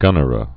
(gŭnər-ə, gə-nĭrə)